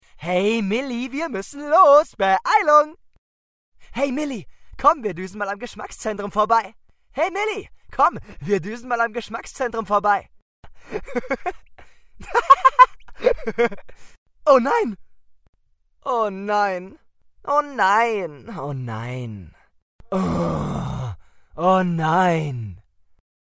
Hörbuch Erzählung Werbung Demo Hörbuch Hörspiel Voice Over Doku Comic